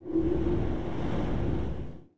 walk_wind.wav